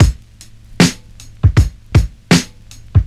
• 78 Bpm Drum Loop A# Key.wav
Free breakbeat sample - kick tuned to the A# note. Loudest frequency: 955Hz
78-bpm-drum-loop-a-sharp-key-xT6.wav